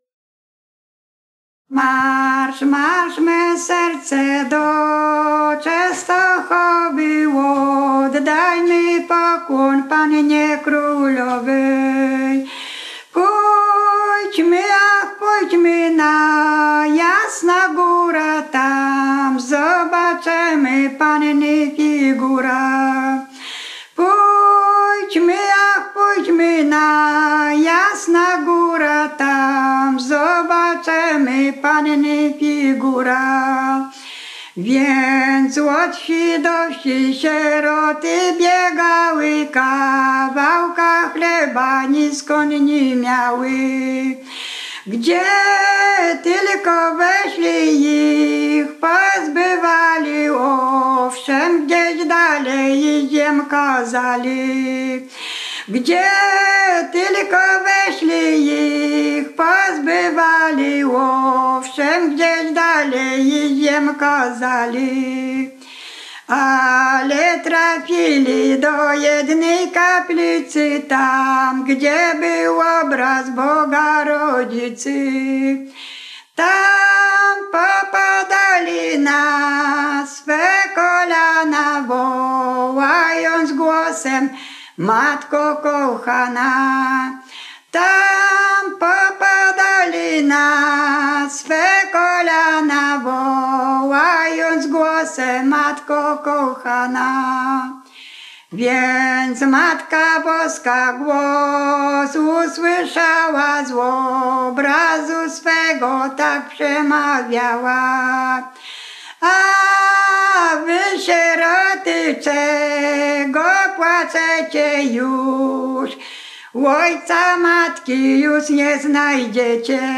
Roztocze
Pielgrzymkowa
katolickie nabożne pielgrzymkowe